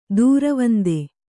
♪ dūravande